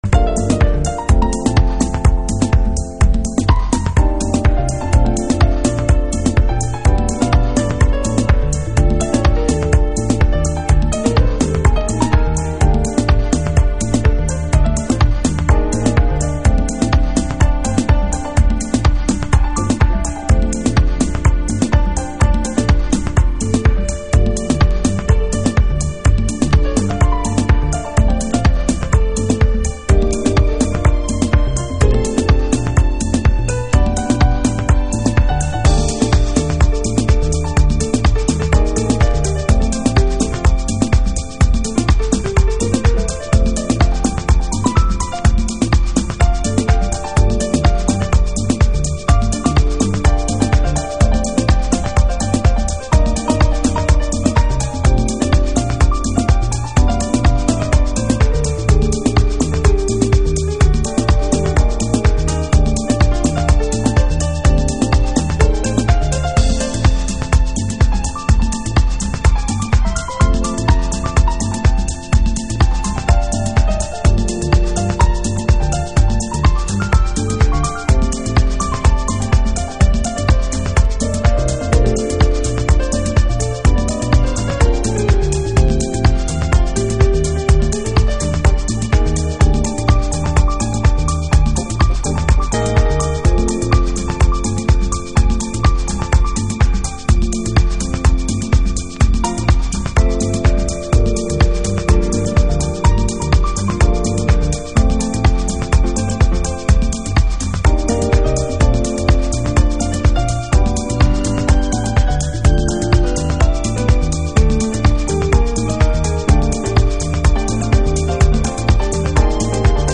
中低域のグルーヴとアコースティックが混然一体となってグルーヴするのがUS DEEPの美鳴。
Chicago Oldschool / CDH